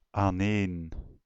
Ääntäminen
Synonyymit ineen opeenvolgend Ääntäminen Tuntematon aksentti: IPA: /aːnˈeːn/ Haettu sana löytyi näillä lähdekielillä: hollanti Käännöksiä ei löytynyt valitulle kohdekielelle.